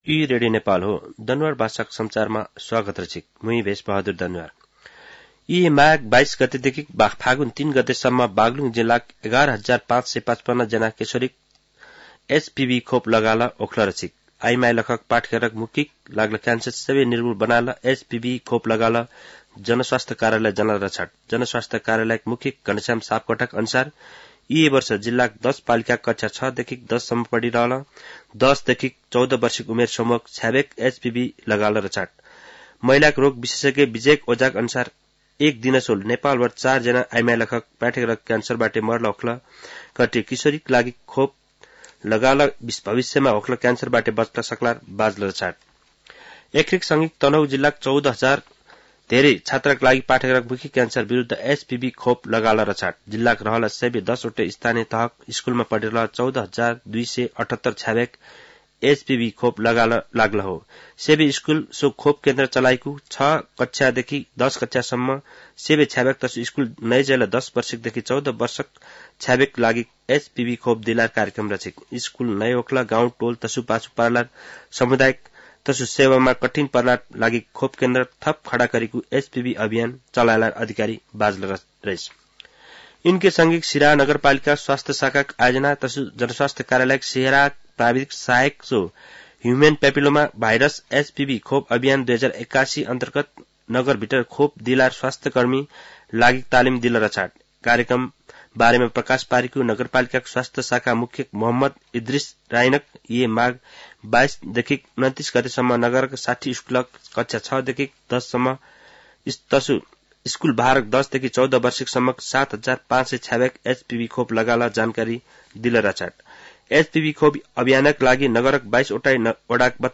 दनुवार भाषामा समाचार : १० माघ , २०८१
Danuwar-news-1-4.mp3